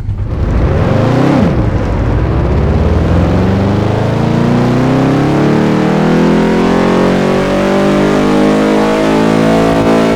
Index of /server/sound/vehicles/lwcars/dodge_daytona
rev.wav